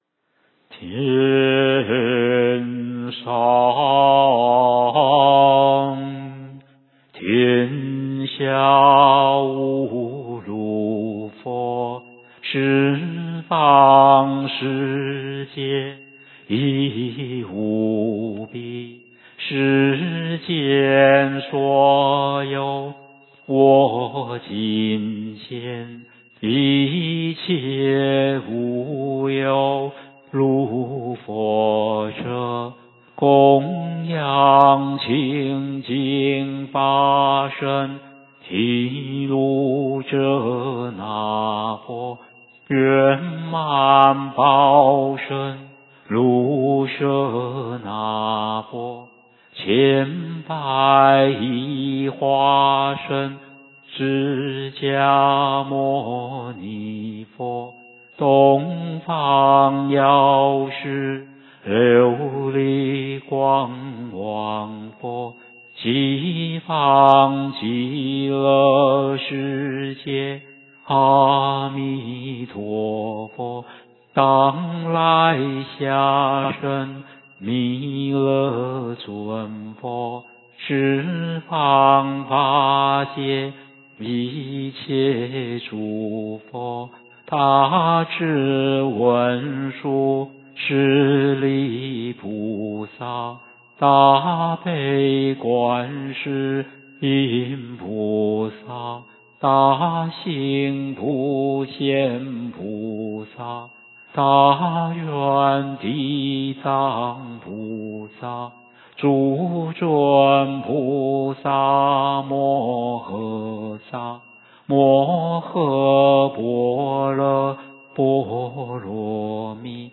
梵呗教学音档
二时临斋．早斋(粥) (有引磬)